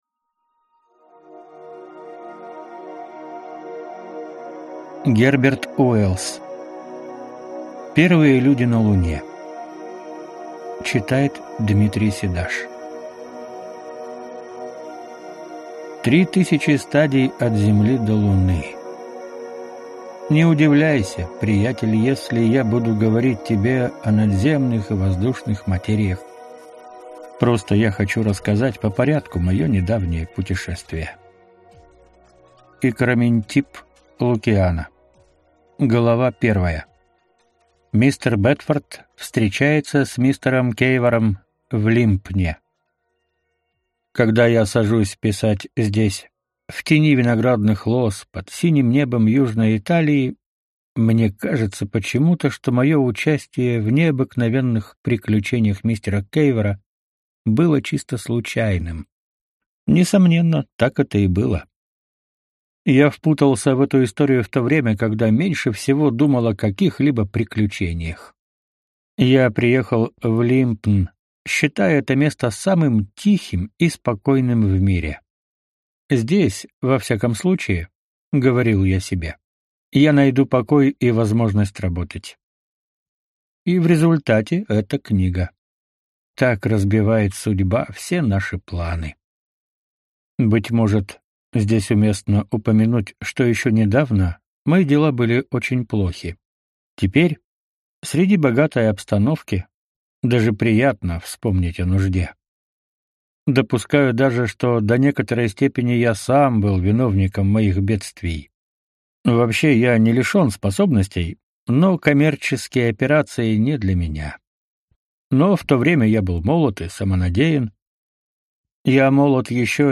Аудиокнига Первые люди на Луне | Библиотека аудиокниг